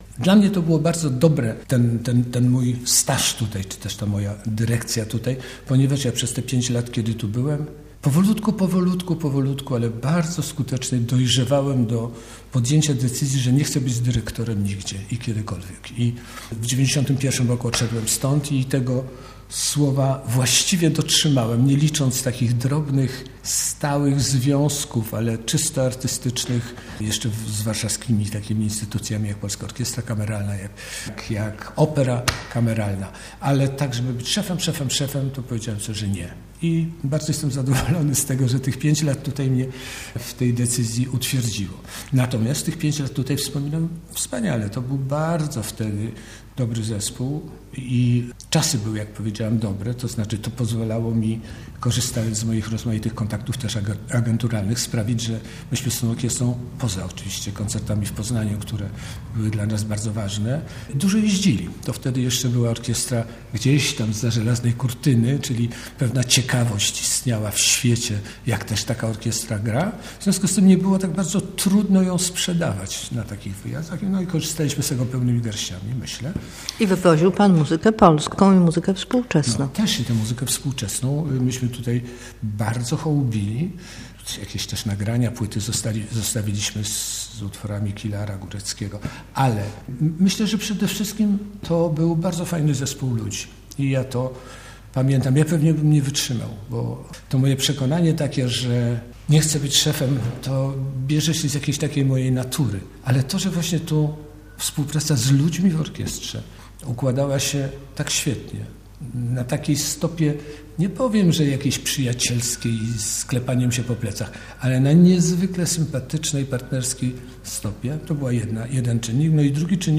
To była rozmowa z 2024 roku a podczas wcześniejszego spotkania przed mikrofonem swój zawód dyrygenta definiował tak.